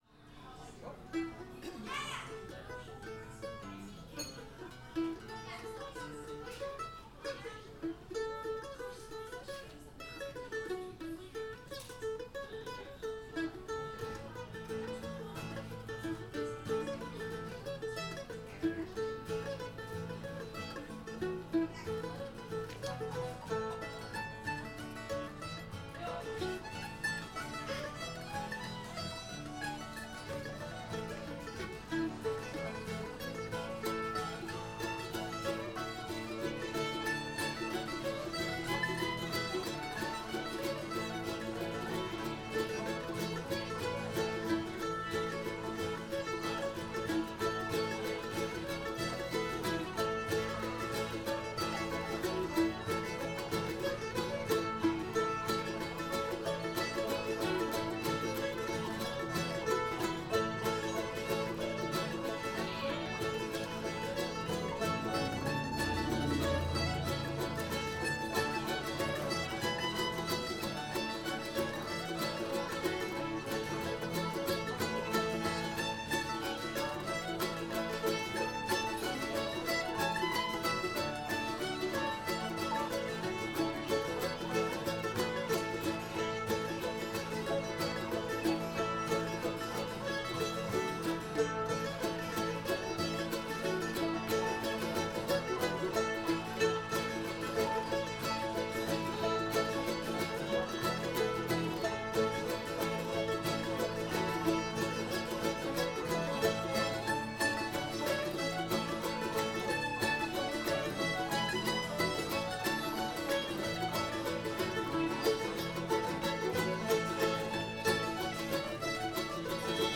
tar river [A]